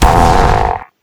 pl_impact_airblast2.wav